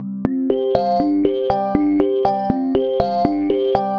Star Light Lead.wav